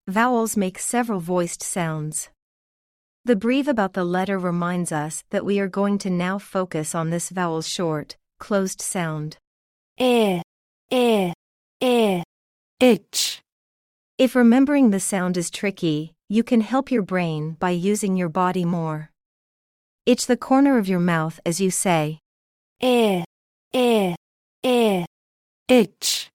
I-itch-lesson-AI-1.mp3